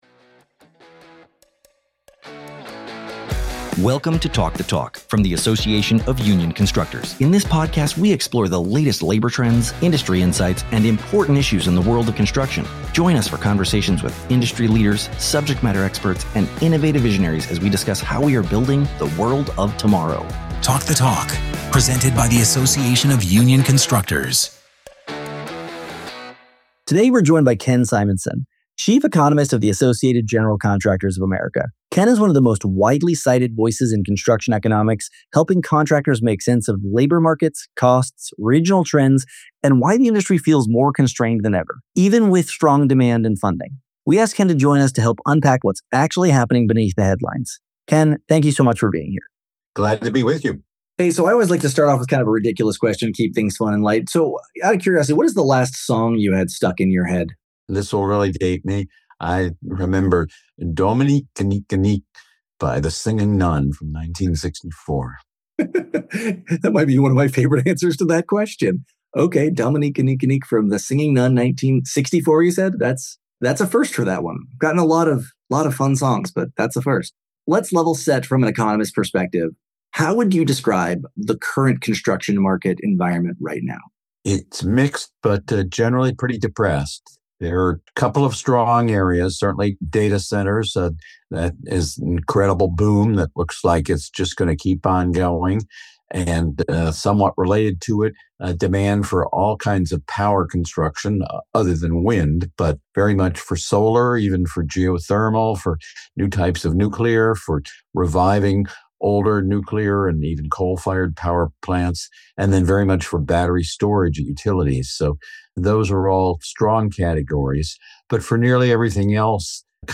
The conversation covers data center and power construction booms, labor cost trends across four different measures, and why the Architecture B